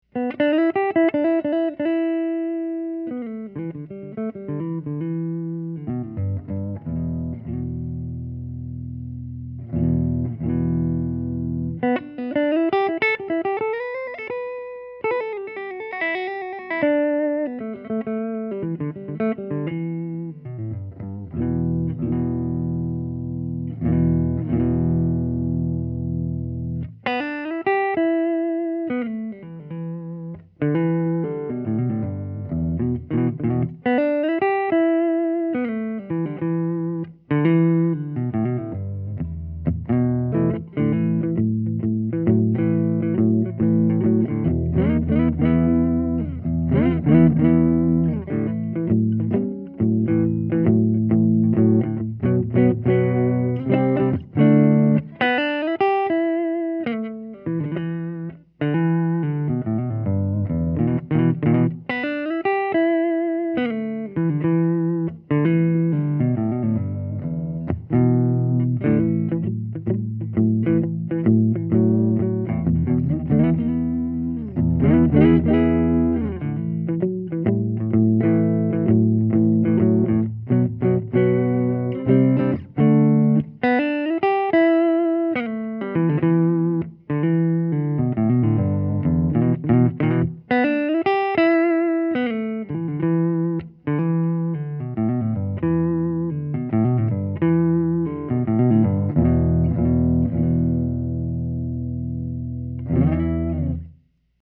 This is a world-class electric archtop guitar, with a fast, straight neck, and wonderful tone from the Benedetto A-6 pickup.
Here are 12 quick, 1-take MP3 sound files of myself playing this guitar, to give you an idea of what to expect. The guitar has great tone, sustain, and body, and is very easy and very fun to play. The guitar is going through a Dr. Z MAZ Junior 18w 2x10 tube amp, and then stereo mic'ed with a Neumann U87 and a Sennheiser MD441 and recorded straight into a Sony PCM D1 flash recorder, and MP3s were made in Logic, with no EQ or effects.
(Original, in G)